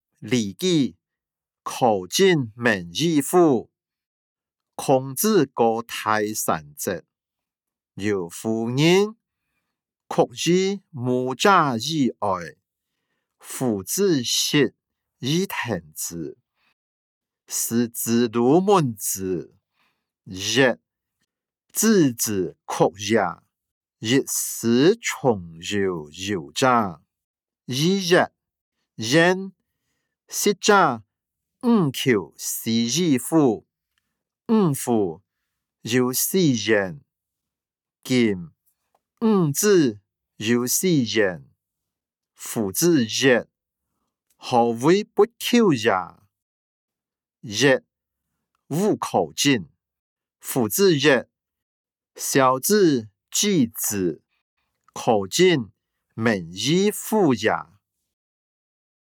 經學、論孟-苛政猛於虎音檔(饒平腔)